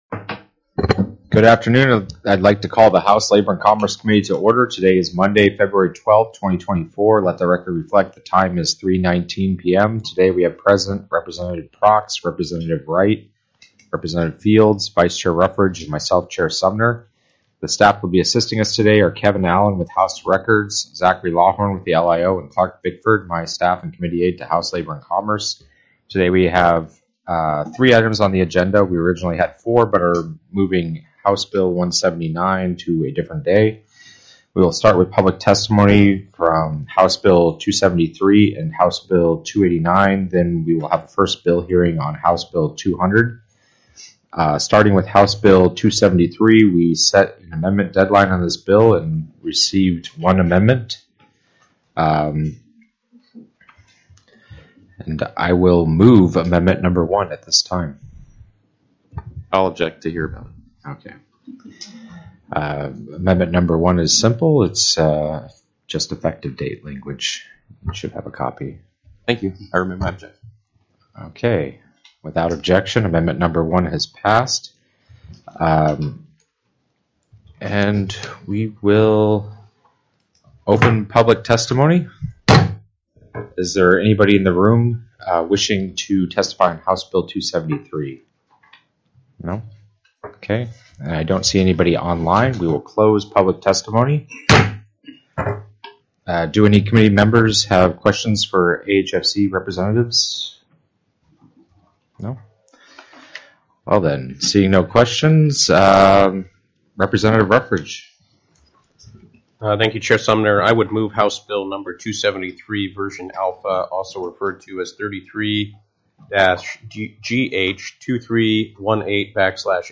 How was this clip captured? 02/12/2024 03:15 PM House LABOR & COMMERCE The audio recordings are captured by our records offices as the official record of the meeting and will have more accurate timestamps.